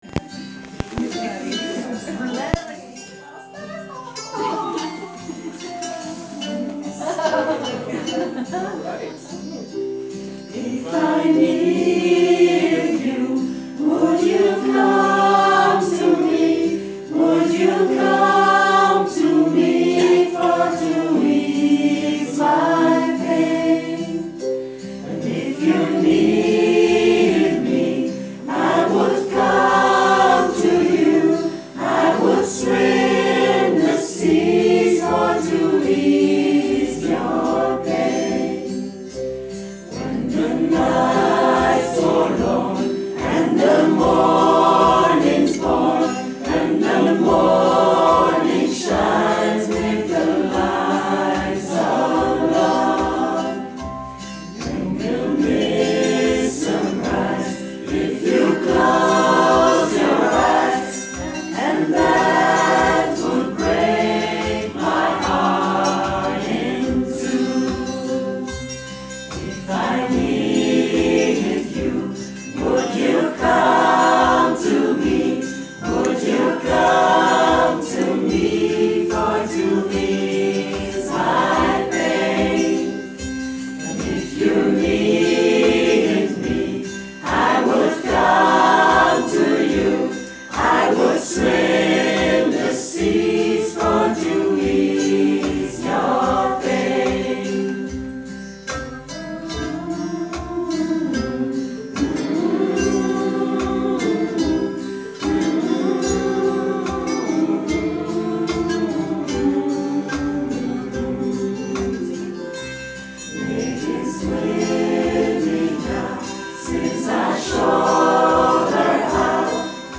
Een koorworkshop.
Popkoor geen popcorn
Op het einde van de workshop wordt opnieuw het eerste liedje gezongen. in een kleine cirkel.